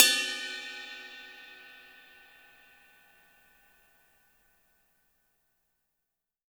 -RIDEBELL -R.wav